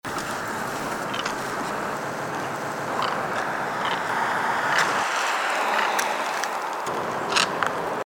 Great Egret
This bird's call is a low, hoarse croak or kuk-kuk-kuk.
great-egret-call.mp3